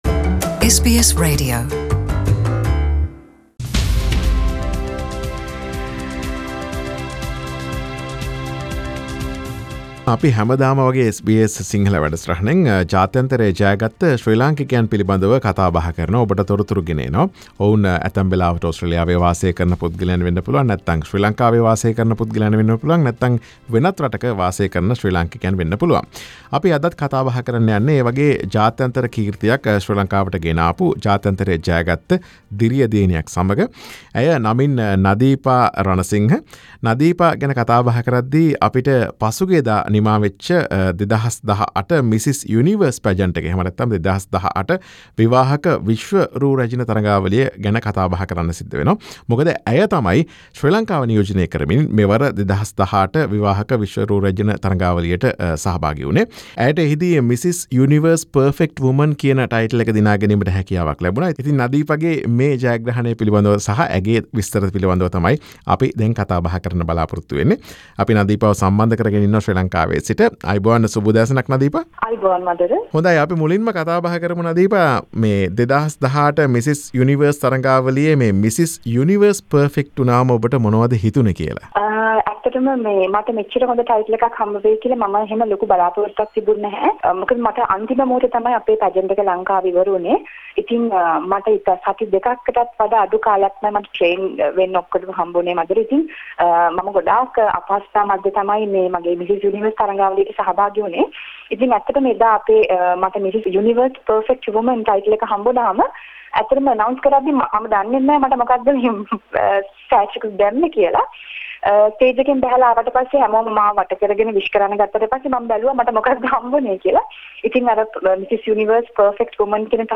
SBS සිංහල වැඩසටහන ඇයත් සමග ඇගේ ජයග්‍රහණය පිළිබඳ සිදුකළ සාකච්ඡාව.